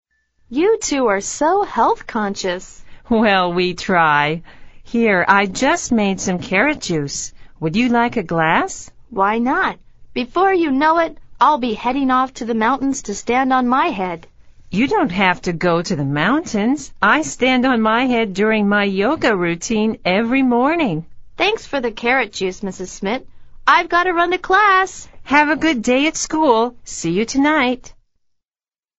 美语会话实录第111期(MP3+文本):Routine